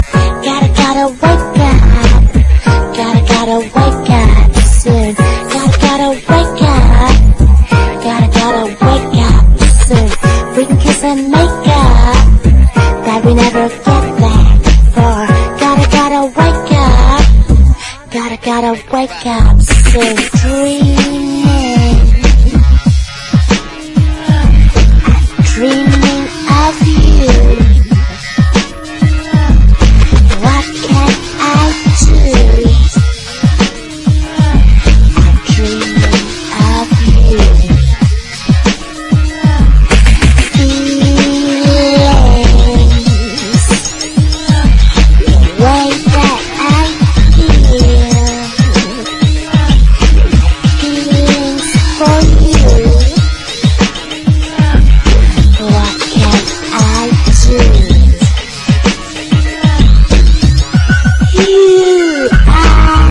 アタマからラストまでフロアをブチあげるハイ・テンションなバッキバキのレイヴ・サウンドです！